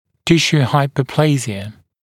[‘tɪʃuː ˌhaɪpə(u)’pleɪʒɪə] [-sjuː][‘тишу: ˌхайпо(у)’плэйжиэ] [-сйу:]гипоплазия тканей